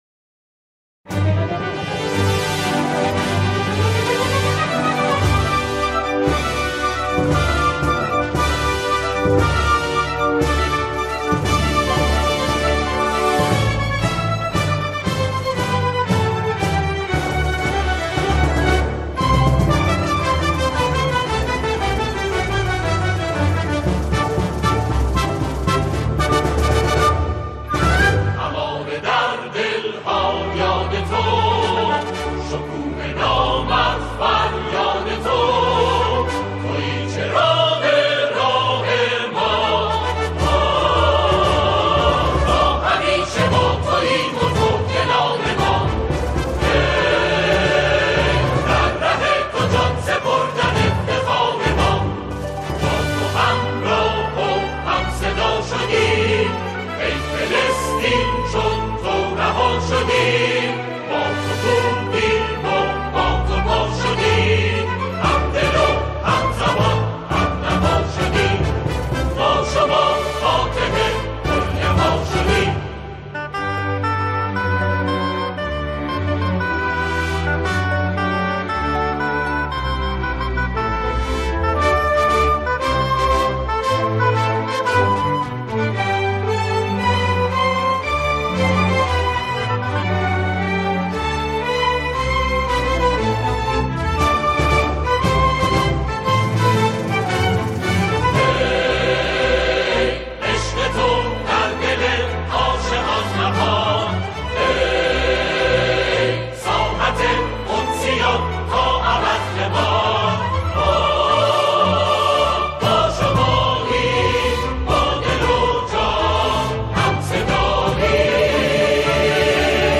در این قطعه، آن‌ها، شعری را درباره فلسطین می‌خوانند.